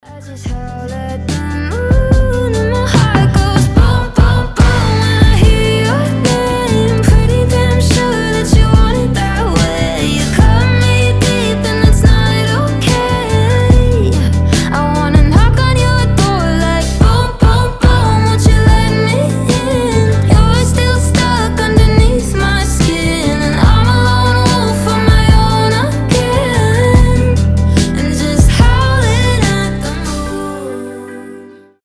• Качество: 320, Stereo
милые
красивый женский голос